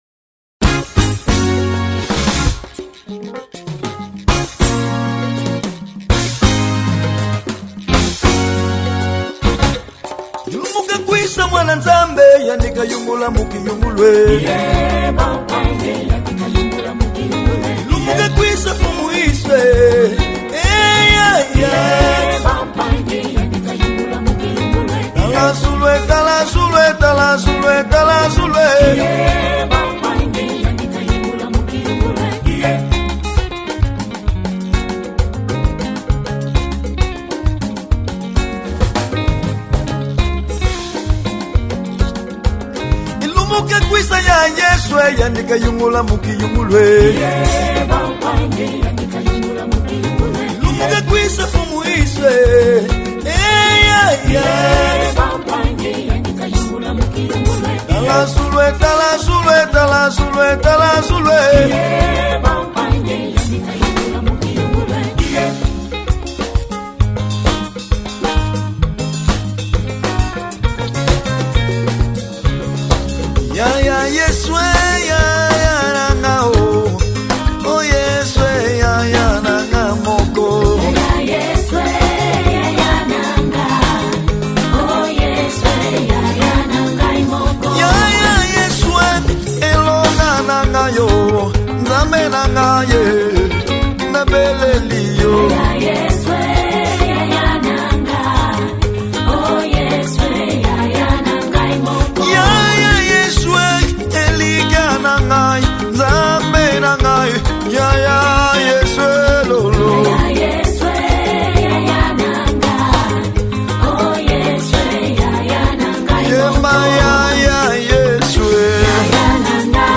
Gospel 2012